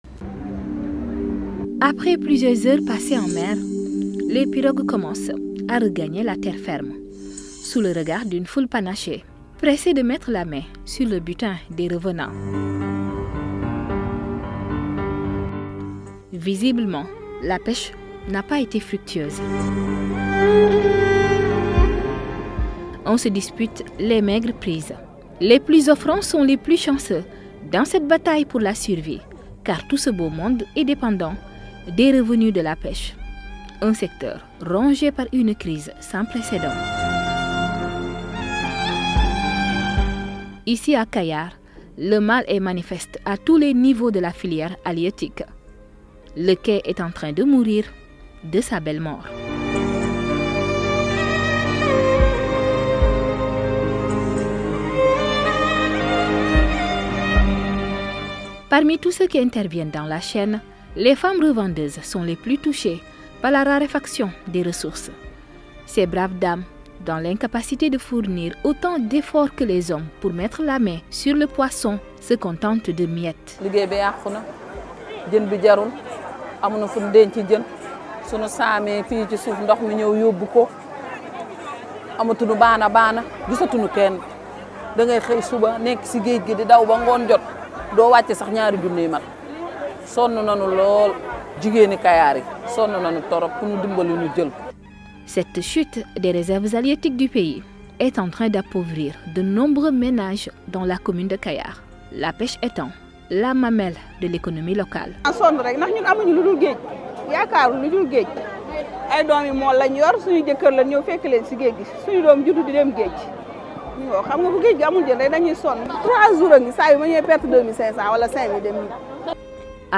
Le reportage sonore offre un regard intime sur la vie des pêcheurs de Kayar et les défis auxquels ils sont confrontés en raison du changement climatique. Des témoignages poignants révèlent la diminution des prises de poissons, la détérioration des habitats marins et les conditions météorologiques de plus en plus imprévisibles qui rendent la pêche plus difficile et dangereuse.